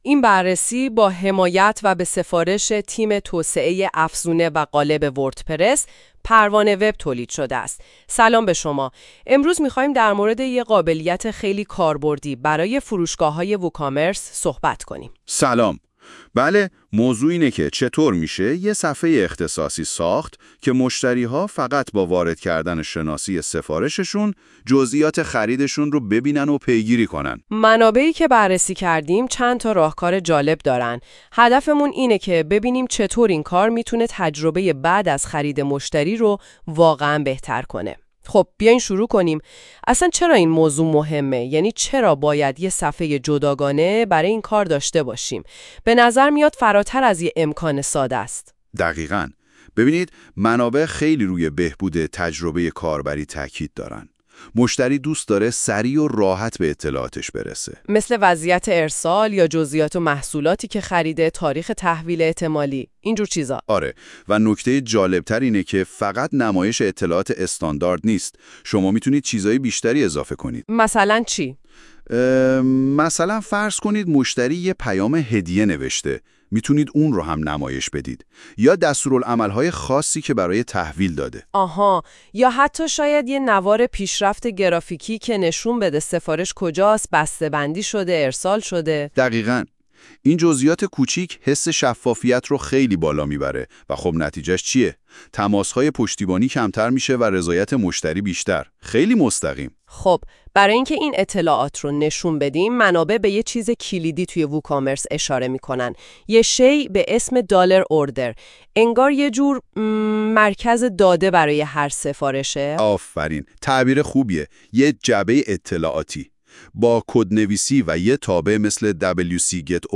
قبل از شروع مقاله ، اگه حوصله یا فرصت مطالعه این مقاله رو ندارید ، پیشنهاد می کنیم پادکست صوتی زیر که با ابزار هوش مصنوعی (گوگل notebooklm ) به زبان فارسی توسط تیم پروان وب تولید شده است ، گوش کنید.